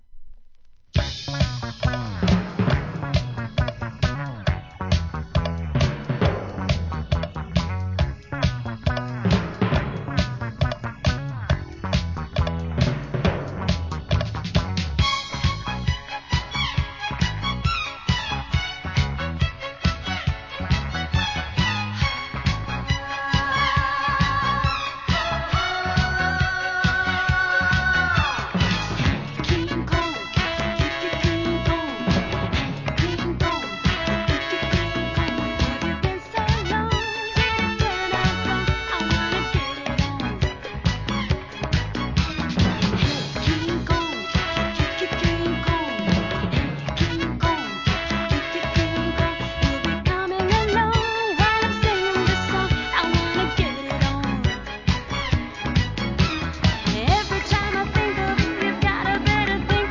店舗 数量 カートに入れる お気に入りに追加 キング・コングも踊りだす1979年DISCO!!